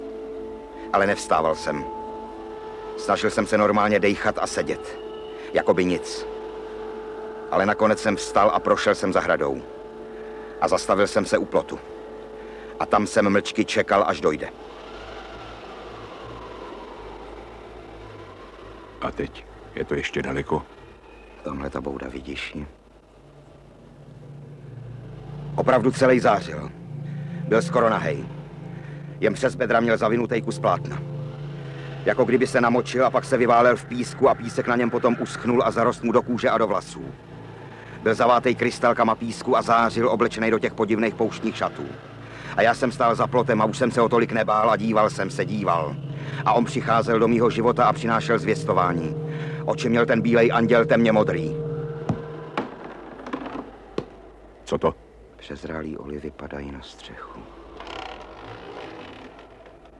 Audiobook
Audiobooks » Short Stories
Read: Zdeněk Ornest